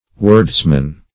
wordsman - definition of wordsman - synonyms, pronunciation, spelling from Free Dictionary
Search Result for " wordsman" : The Collaborative International Dictionary of English v.0.48: Wordsman \Words"man\, n. One who deals in words, or in mere words; a verbalist.